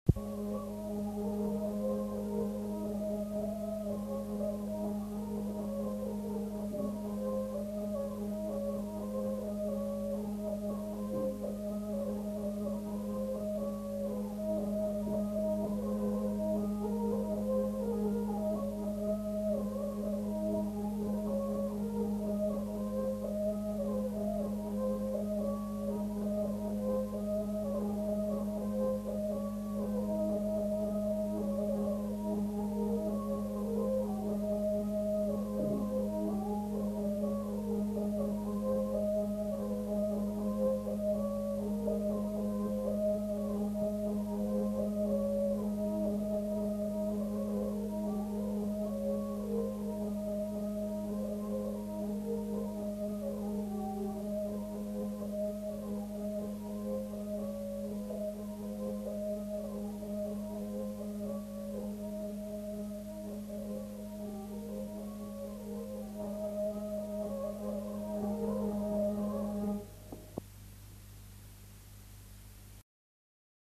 Lieu : Villeneuve-de-Marsan
Genre : morceau instrumental
Instrument de musique : vielle à roue
Danse : rondeau